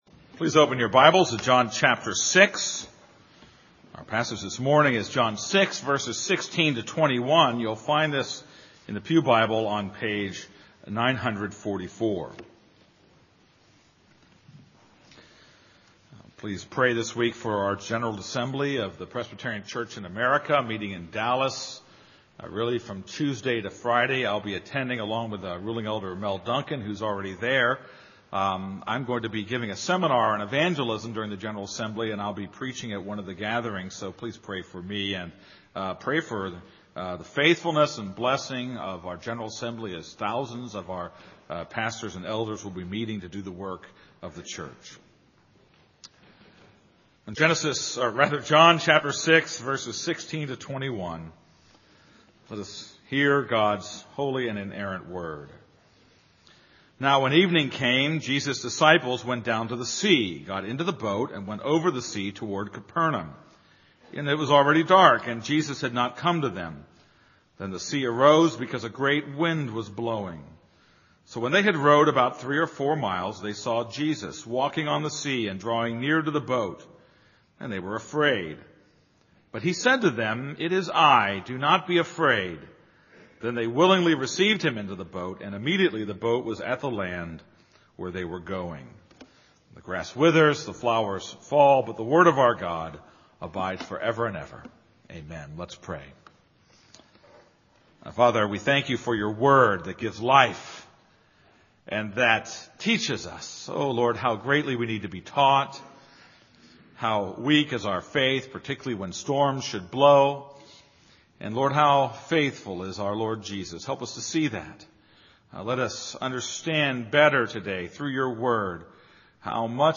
This is a sermon on John 6:16-21.